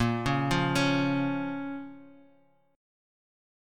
A#m chord